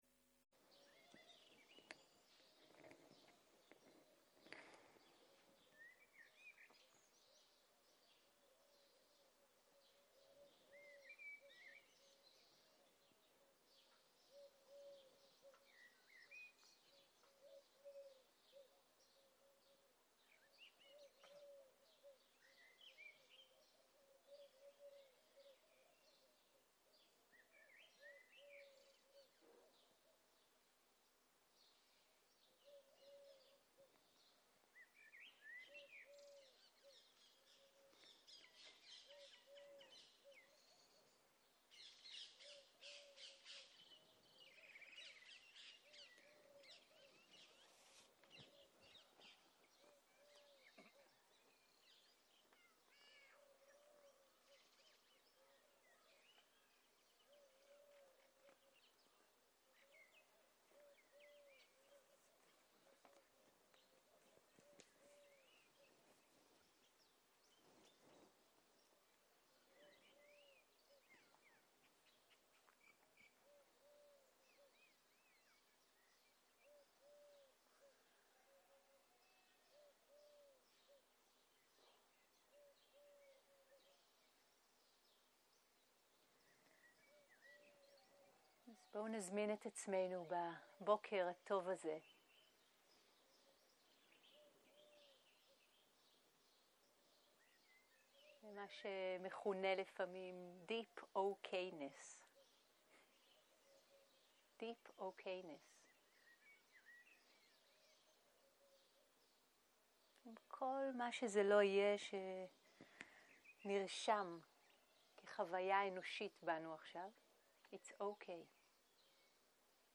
סוג ההקלטה: שיחת הנחיות למדיטציה